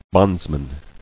[bonds·man]